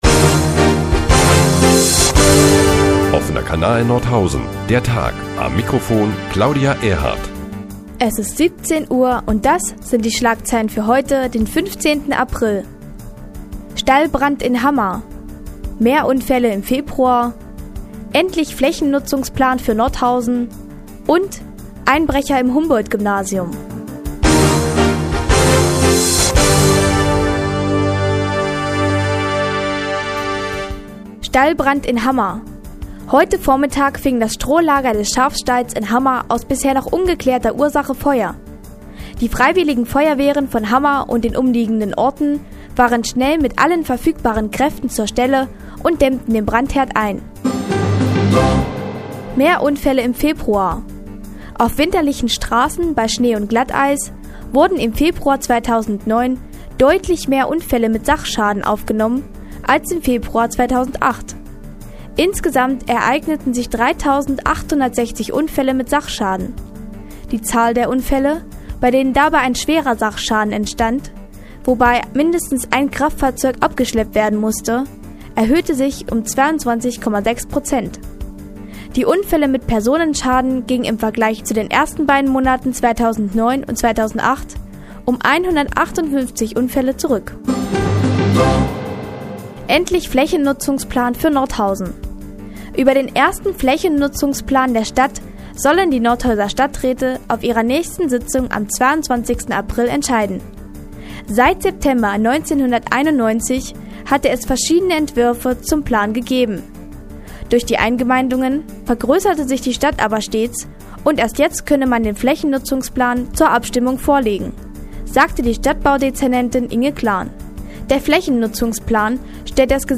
Die tägliche Nachrichtensendung des OKN ist nun auch in der nnz zu hören. Heute unter anderem mit dem Flächennutzungsplan für Nordhausen und dem Einbruch ins Humboldt- Gymnasium.